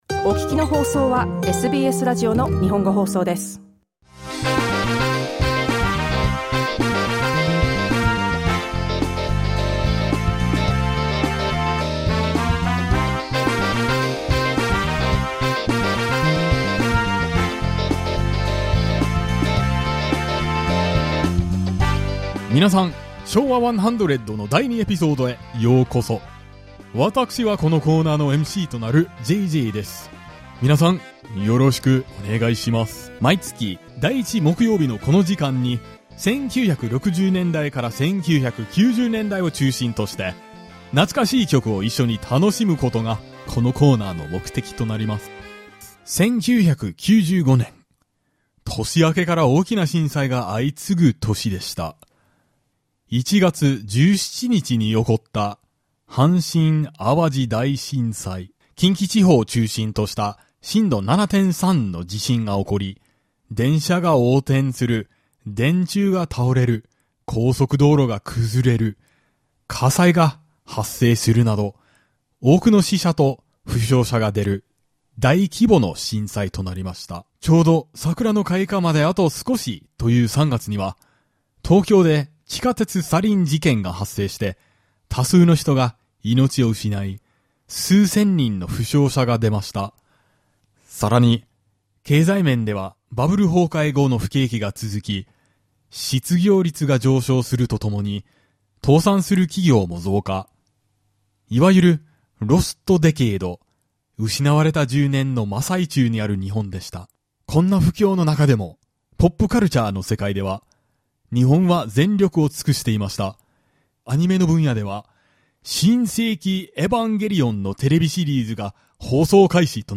If the Showa era in Japan continues, this year marks the centenary of the Showa period. Let's look back on that era while enjoying nostalgic songs from the 1960s to the 1990s.